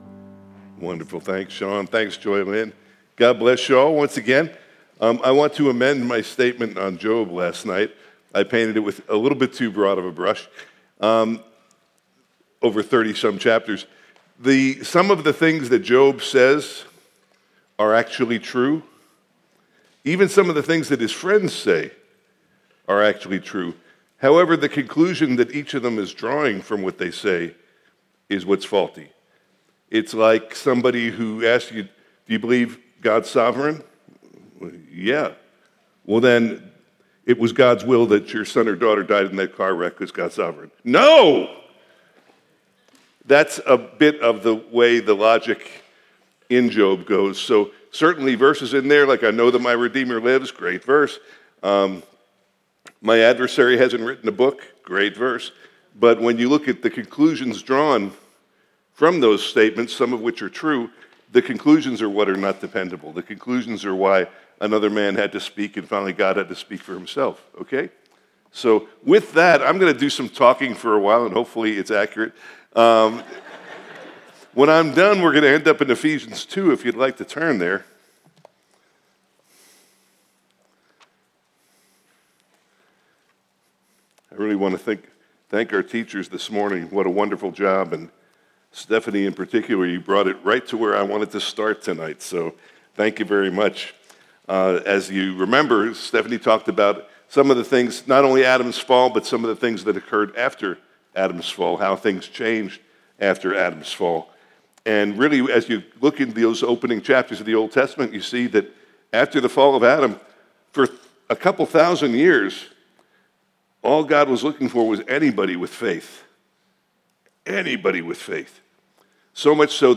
What Is Man? (Family Camp 2025) – Part 4